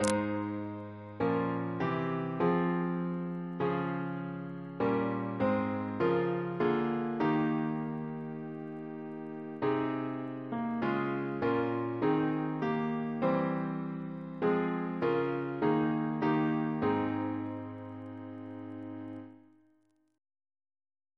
CCP: Chant sampler
Double chant in A♭ Composer: Maurice Greene (1695-1755) Reference psalters: H1982: S10 S271